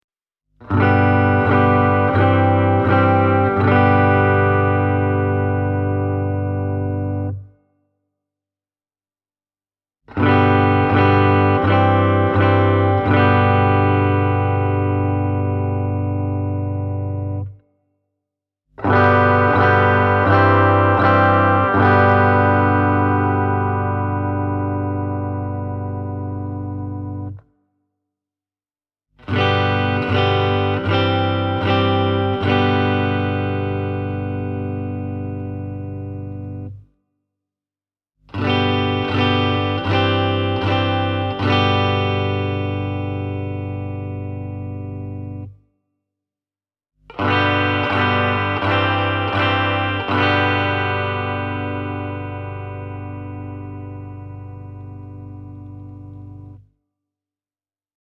Hagströmin omissa ’58 Alnico -humbuckereissa on tässä erikoismallissa avoimet metallikannet, mikä lisännee kitaran soundiin pienen ripauksen diskanttia.
Mikrofonien puolituksen ansiosta Hagström Viking Deluxe Custom -malli taipuu pyöreän ja paksun Gibson-soundimaailman lisäksi myös ohuempiin, Fender-tyylisiin soundeihin, mikä tekee siitä selvästi perinteistä puoliakustista monipuolisemman.
Hagström Viking DLX CSTM – clean:
Hagström-Viking-DLX-CSTM-–-clean.mp3